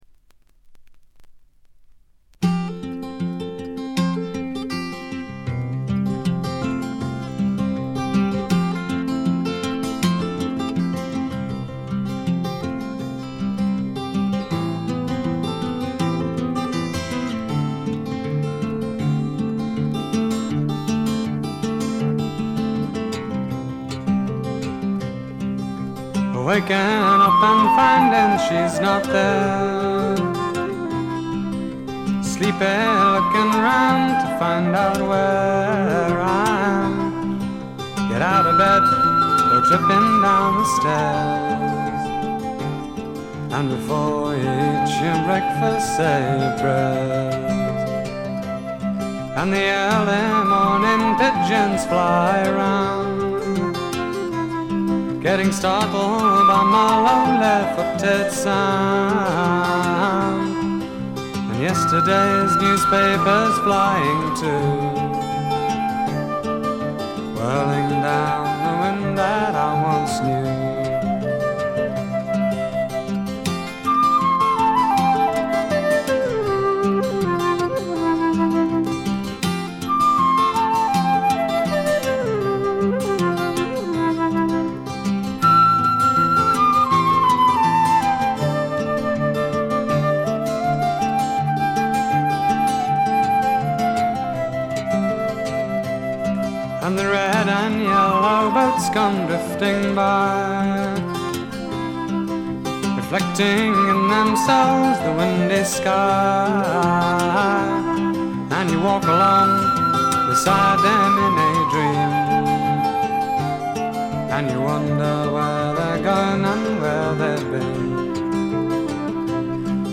わずかなノイズ感のみ。
また本作の特徴として多くの曲でフルートが入りますが、これが実にいいんだなぁ。
試聴曲は現品からの取り込み音源です。